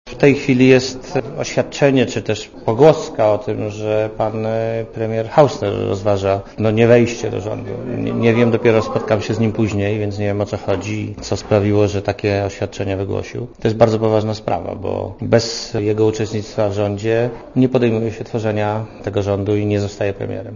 Dla radia ZET mówi Marek Belka (80 KB)